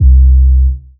TM88 Backup808.wav